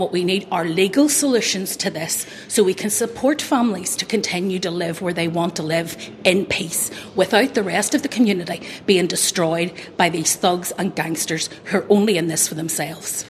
However, Justice Minister Naomi Long says paramilitaries shouldn’t be given a voice or platform………….